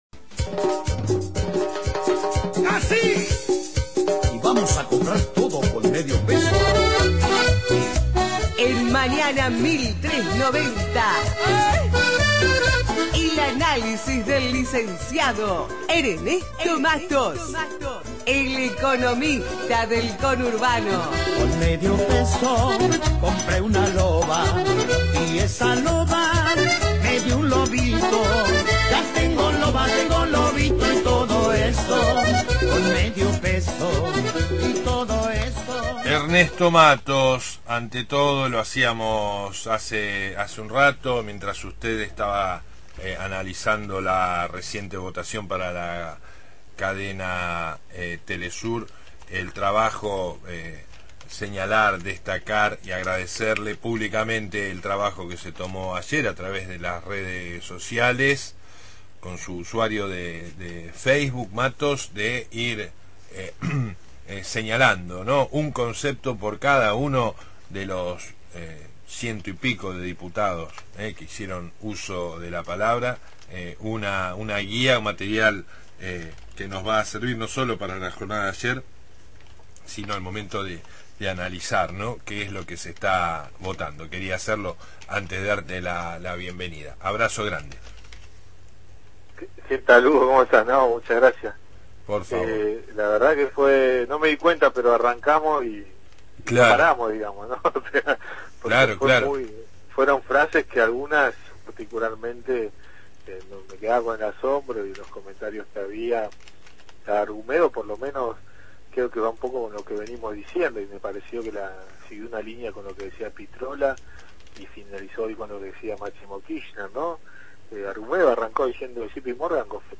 columna de Economía Política